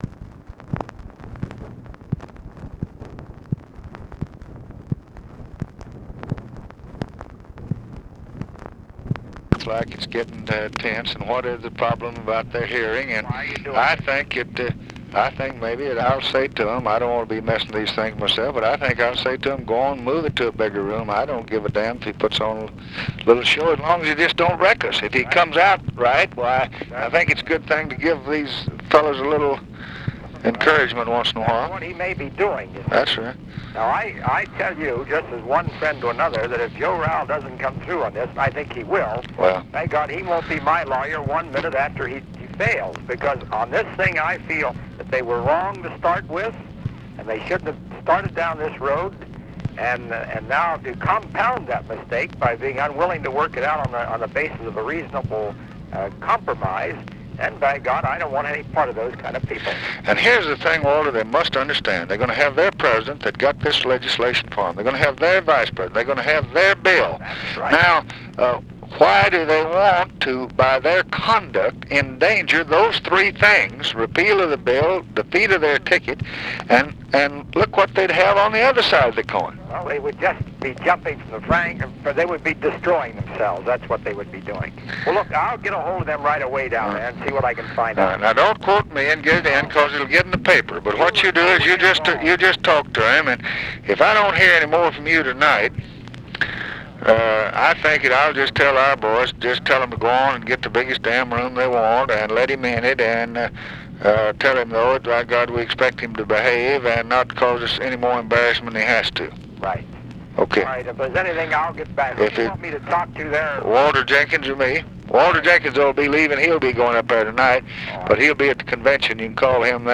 Conversation with WALTER REUTHER and OFFICE CONVERSATION, August 22, 1964
Secret White House Tapes